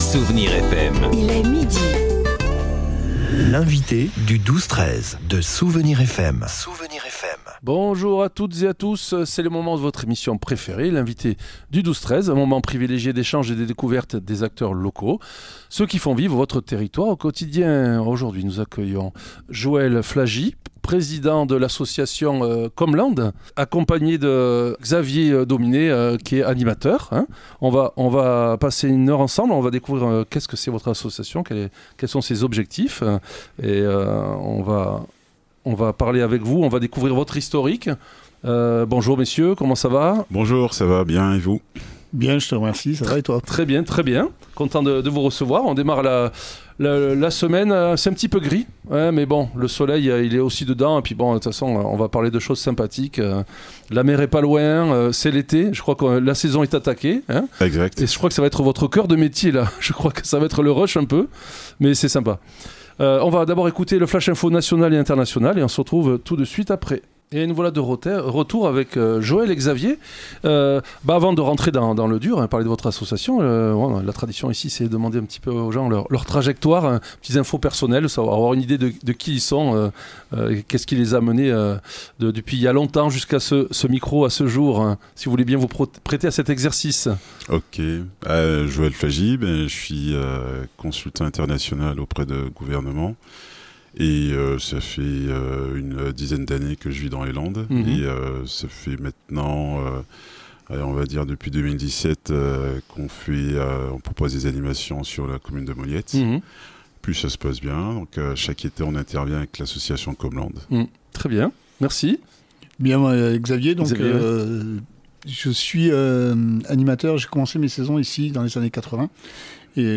L'invité(e) du 12-13 de Soustons recevait aujourd'hui COM'LANDES